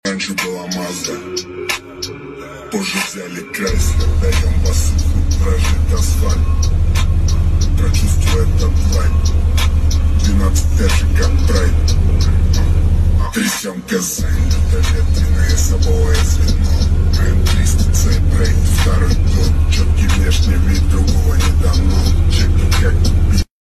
SP 15X 6000 W RMS Subwoofer sound effects free download